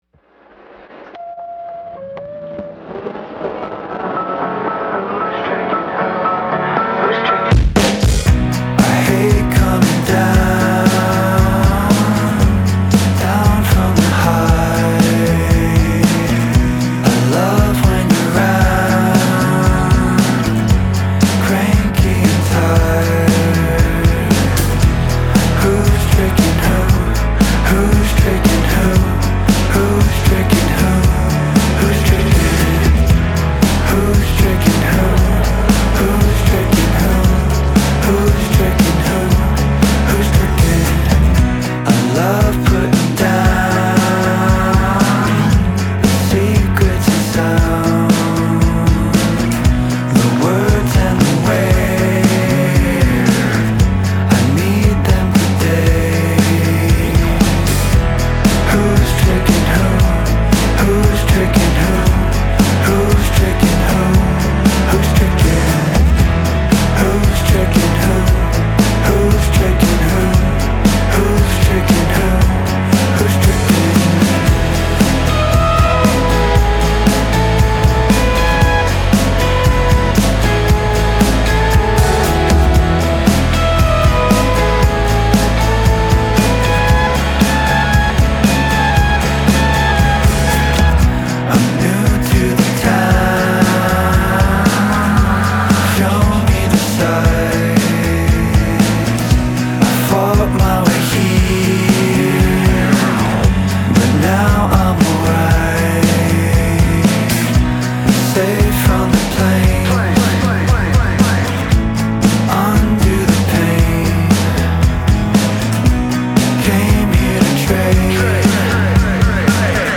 indie rock band
which is a fresh take on 90s/Early 00s style indie rock.